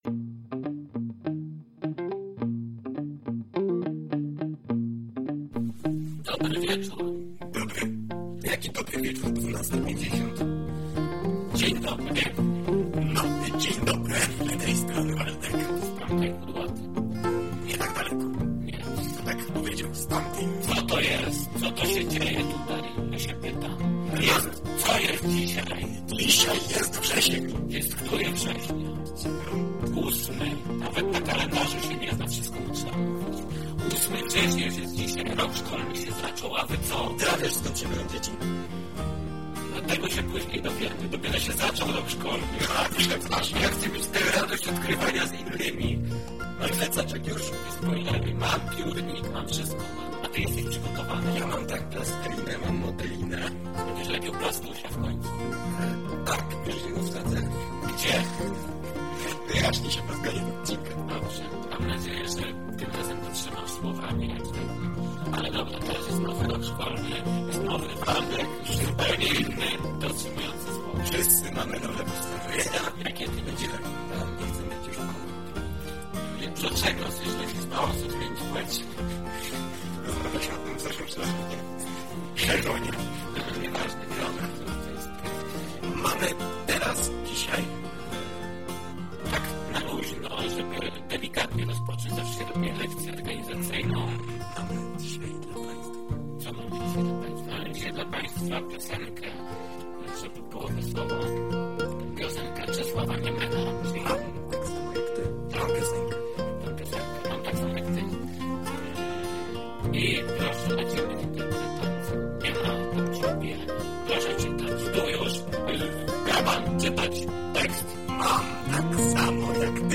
Dłuższy i bardzo wesoły odcinek. A w nim, bardzo dużo koncepcji, intrygujących zwrotów akcji, trochę historii i nasze nowe projekty.
Spodziewaj się dużo śmiechu i otwórz się na trochę nowej wiedzy!
"Co Ałtor Miał na Myśli" to audycja rozrywkowa, nagrywana co tydzień lub dwa.